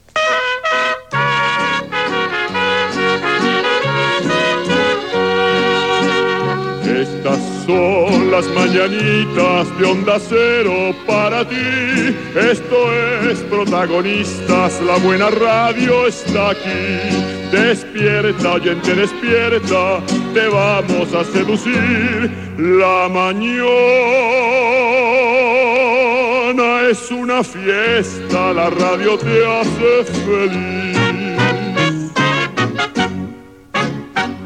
Jingle cantat, a l'estil mexicà, del programa